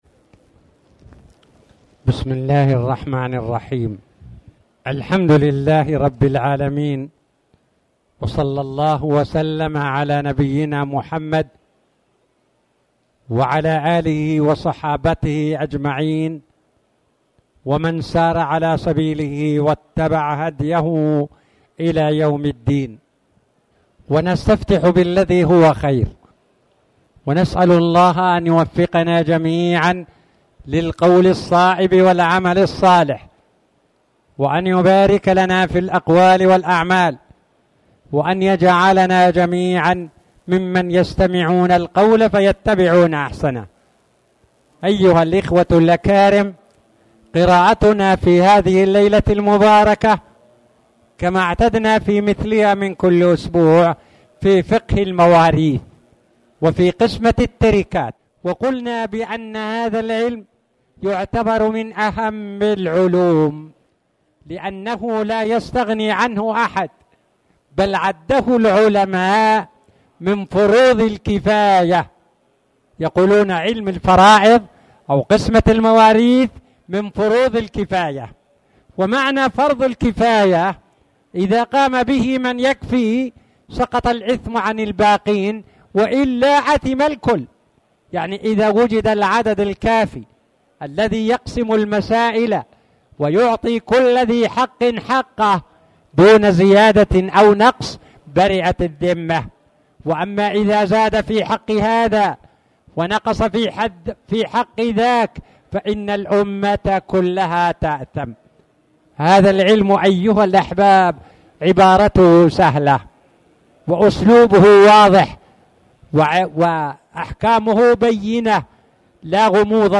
تاريخ النشر ١٤ صفر ١٤٣٨ هـ المكان: المسجد الحرام الشيخ